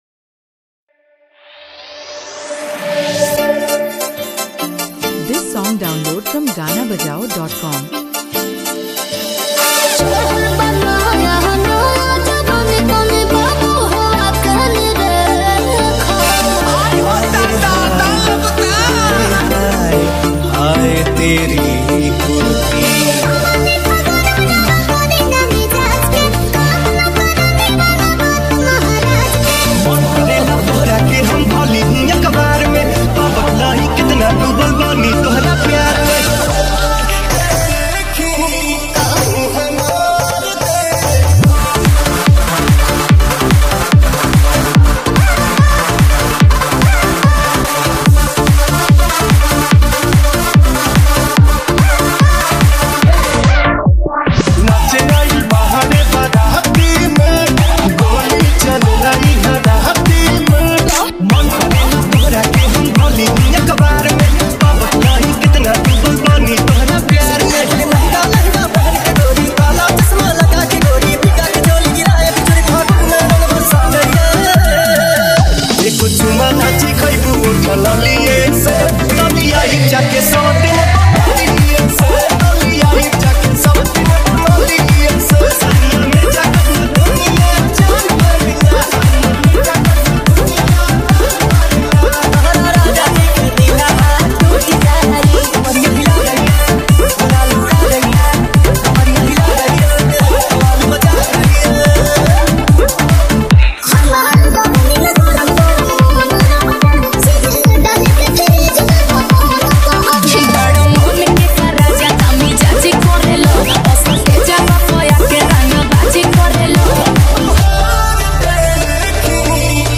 Bass Remix New Year Bhojpuri Party Songs